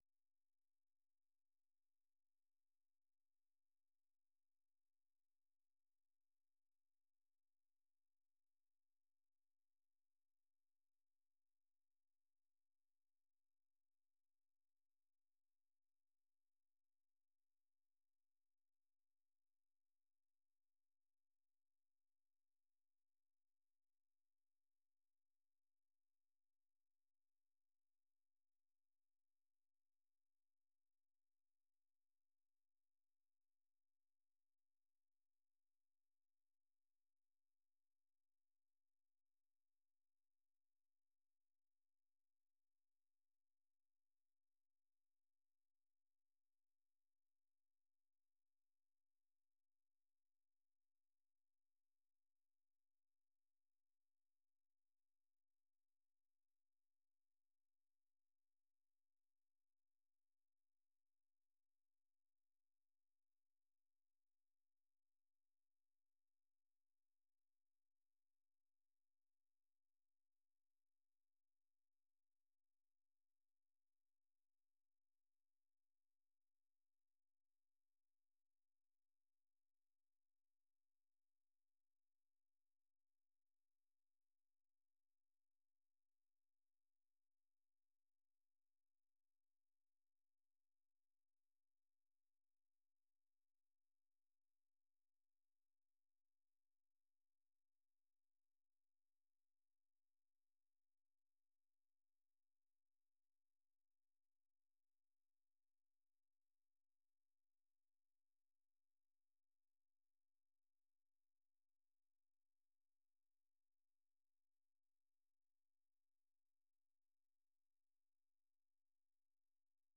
ข่าวสดสายตรงจากวีโอเอ ภาคภาษาไทย 8:30–9:00 น.